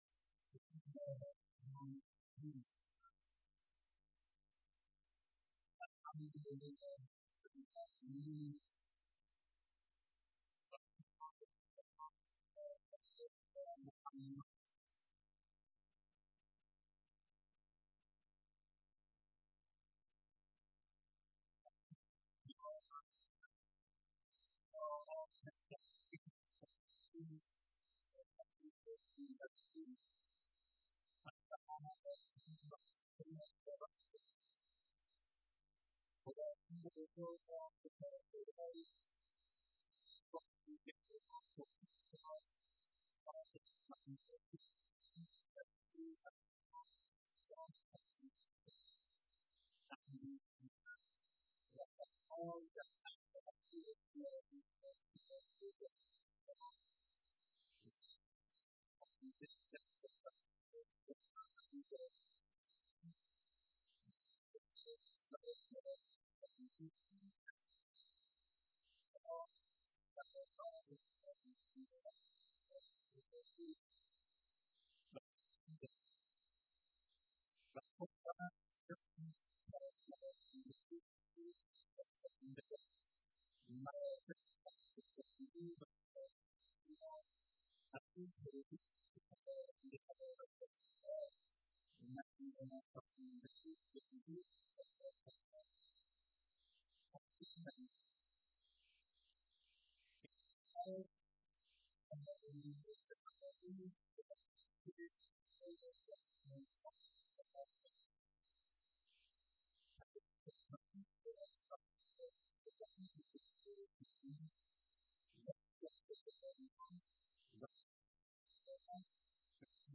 بیانات در دیدار شركت كنندگان در اجلاس بين المللی زنان و بيداری اسلامی
دریافت : mp4 68mb مدت : 32:03 صوت / بیانات در دیدار شركت كنندگان در اجلاس بين المللی زنان و بيداری اسلامی 21 /تیر/ 1391 دریافت : mp3 7mb مدت : 31:33 صوت / ارائه گزارش آقای دكتر ولايتی دبير كل مجمع جهانی بيداری اسلامی 21 /تیر/ 1391 دریافت : mp3 2mb مدت : 06:38